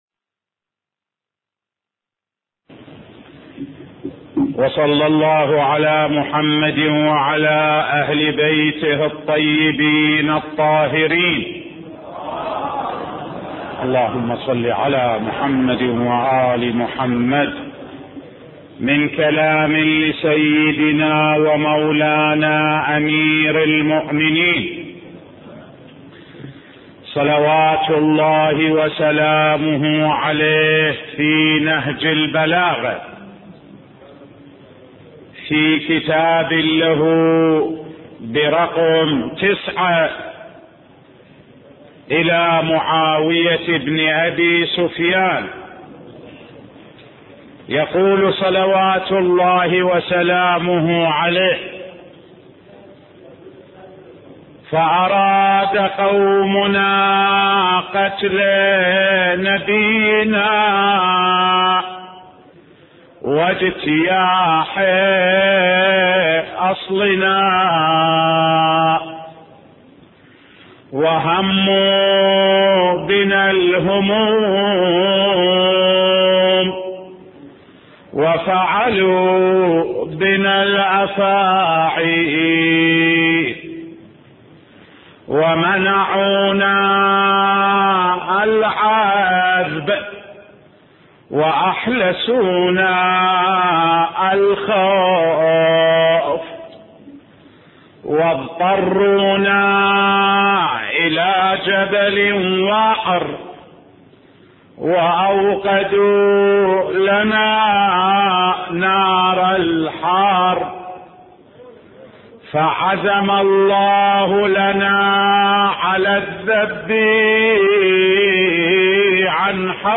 سلسلة دروس في نهج البلاغة (15) – الكتاب رقم 9 من نهج البلاغة